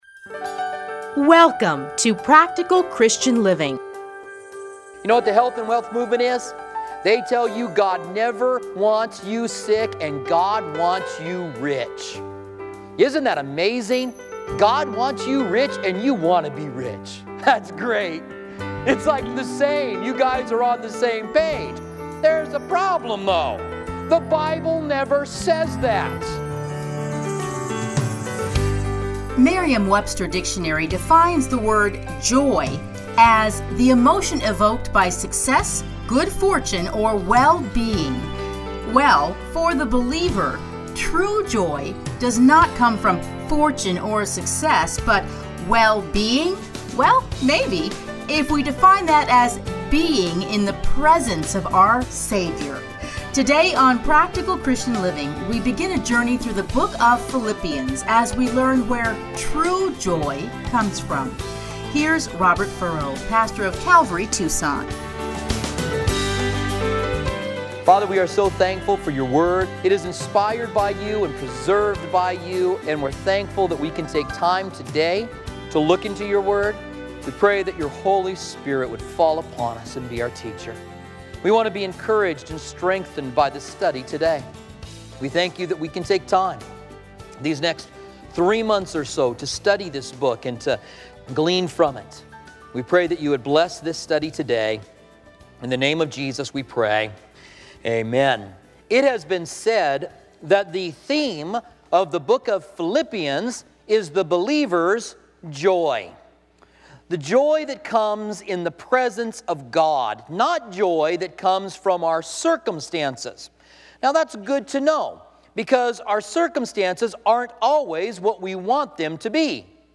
Listen here to his commentary on Philippians.